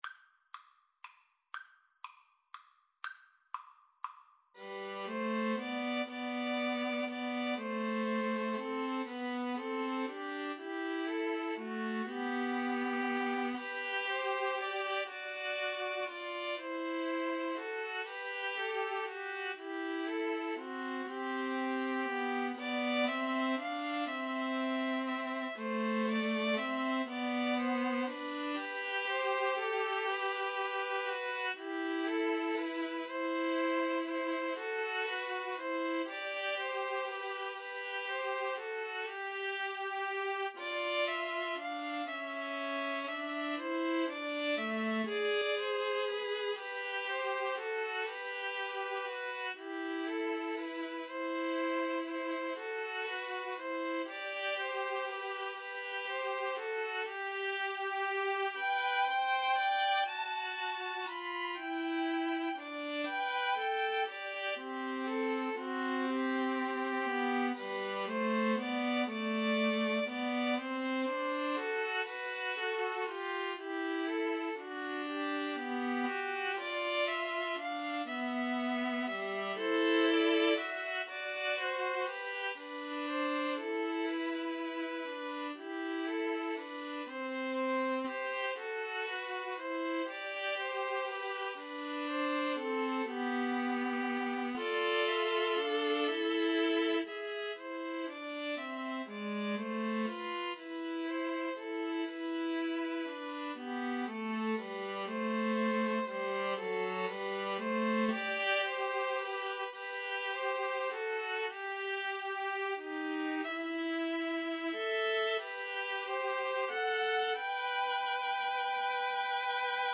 ViolinViolaCello
=120 Andante tranquillo
9/4 (View more 9/4 Music)